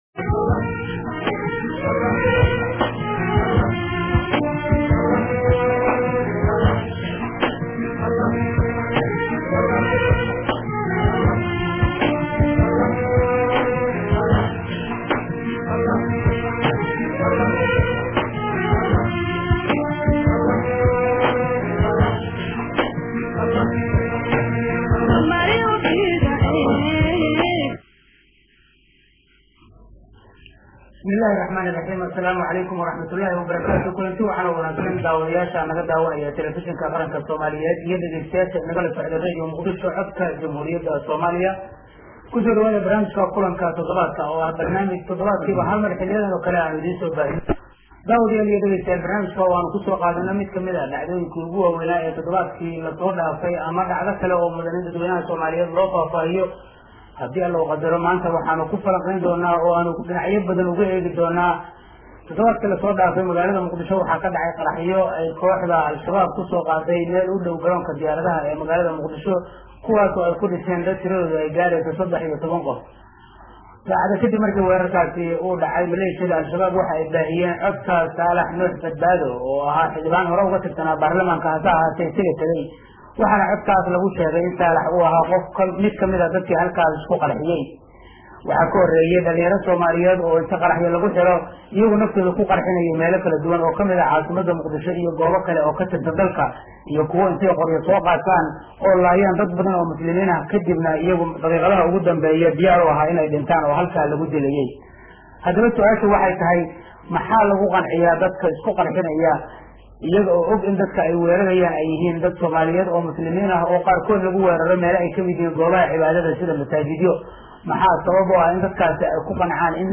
wiil ka tirsanaa jiray Shabaab iyo mas’uuliyiin kale